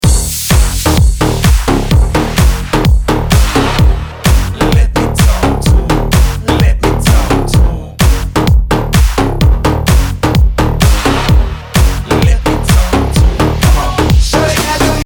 help Старый добрый electro но в новом пикантном звучании
Давно хочу получить подобный звук, переписки с автором не дали особых успехов, знаю что это точно несколько слоёв massive и сэмпл дающий метал